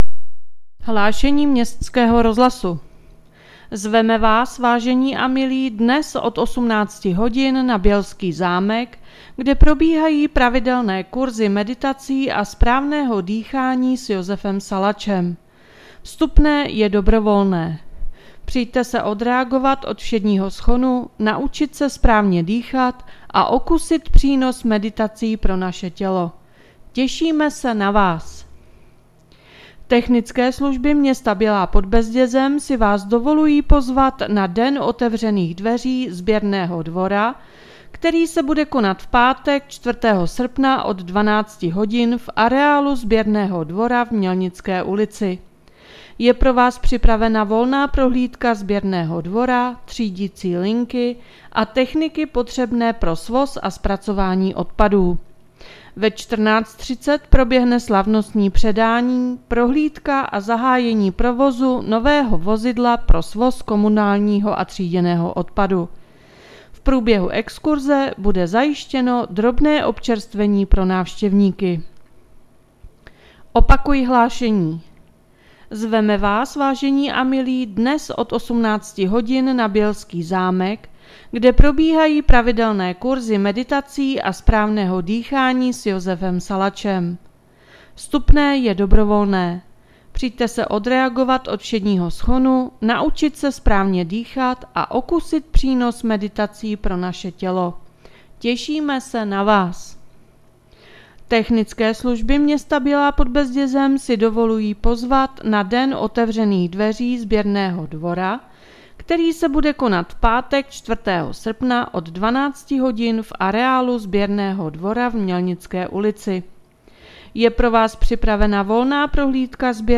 Hlášení městského rozhlasu 31.7.2023